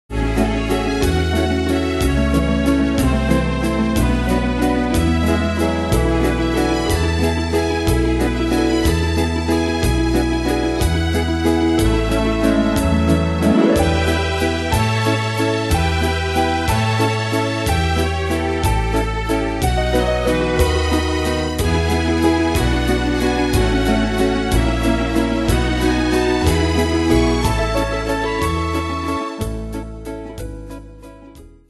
Style: Retro Ane/Year: 1961 Tempo: 184 / 92 Durée/Time: 2.32
Danse/Dance: Valse/Waltz Cat Id.
Pro Backing Tracks